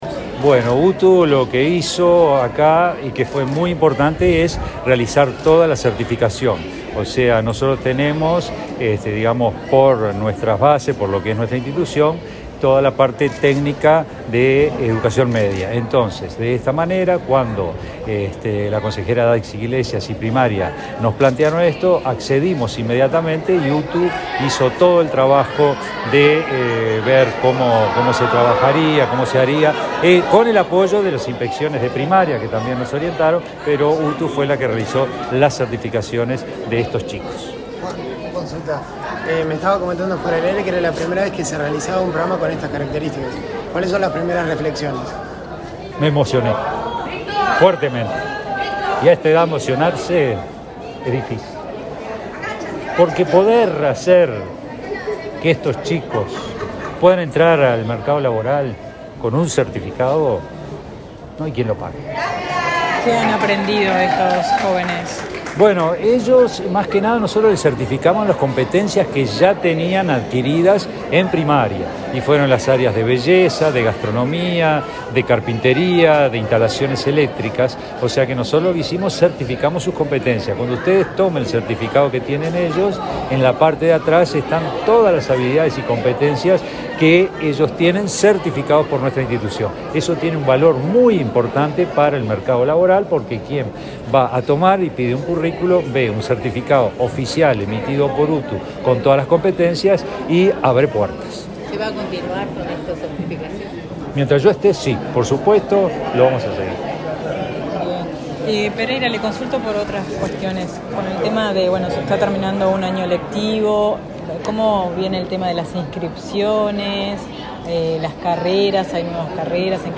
Declaraciones a la prensa del director general de UTU, Juan Pereyra
Declaraciones a la prensa del director general de UTU, Juan Pereyra 13/12/2022 Compartir Facebook X Copiar enlace WhatsApp LinkedIn Este martes 13, en Montevideo, el director general de la UTU, Juan Pereyra, y el presidente de la ANEP, Robert Silva, participaron en la entrega de certificados de acreditación de saberes a 34 jóvenes con discapacidad intelectual. Luego Pereyra dialogó con la prensa.